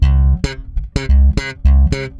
-AL DISCO D.wav